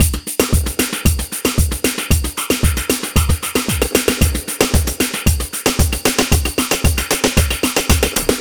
Ala Brzl 3 Drmz Wet 1a.wav